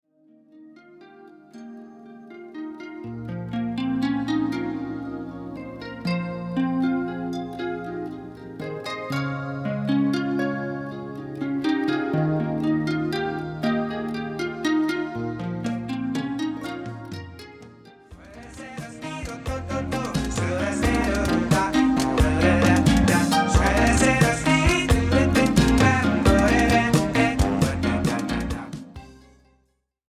at the SINUS-Studio Bern (Switzerland)